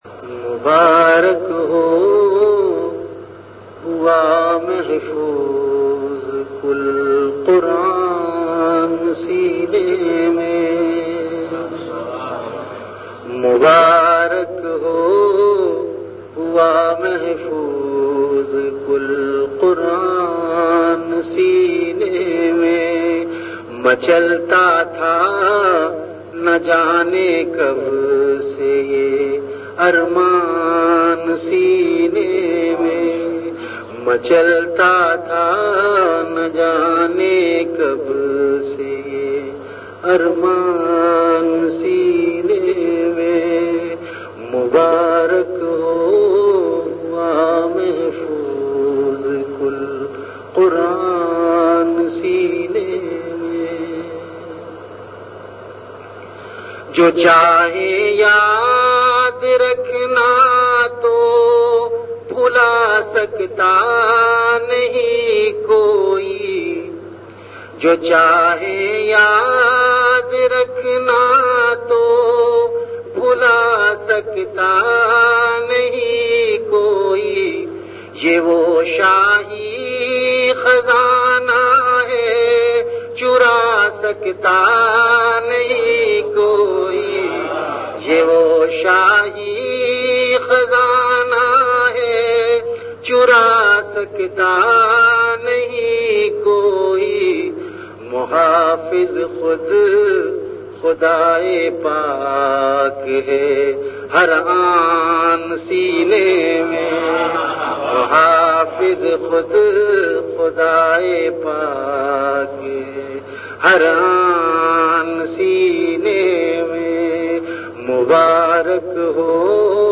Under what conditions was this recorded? Event / TimeAfter Isha Prayer